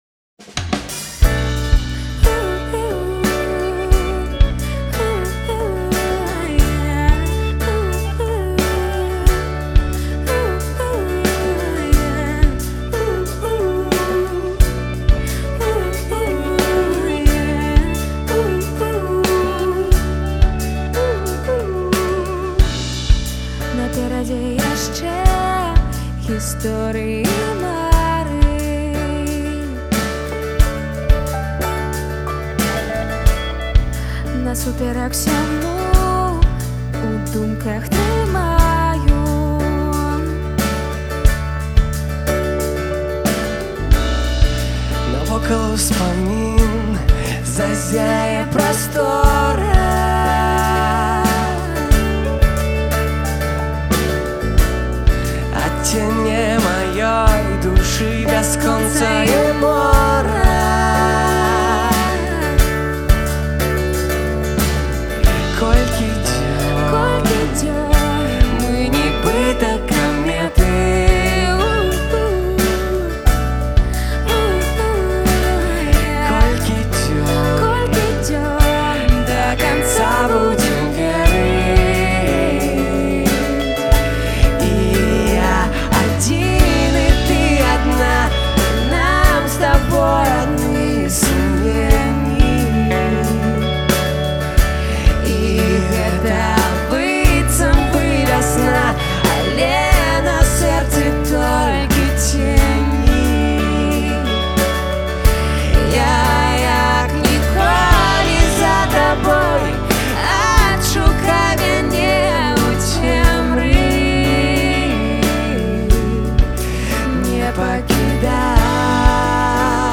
жывы запіс песьні